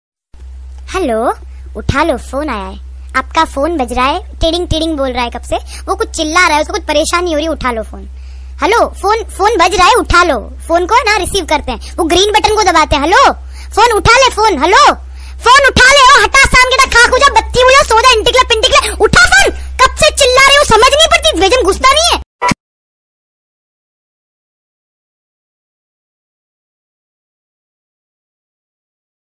funny ringtone pick up the phone